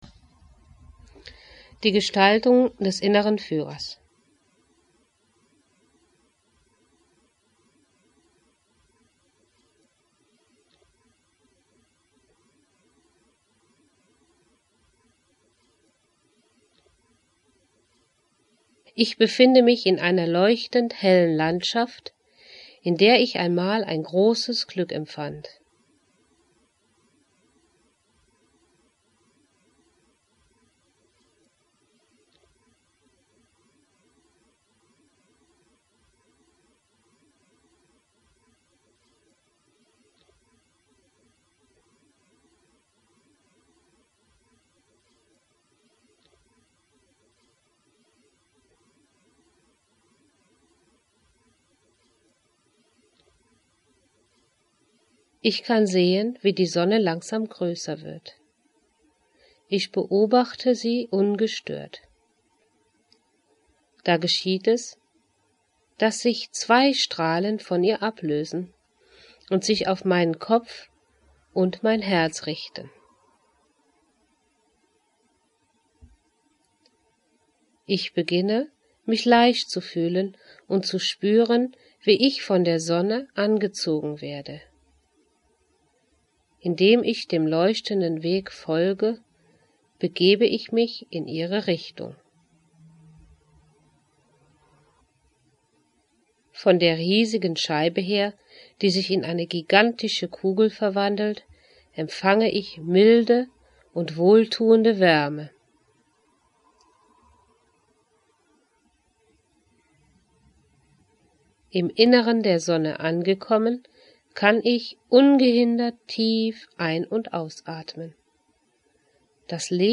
Geleitete Erfahrung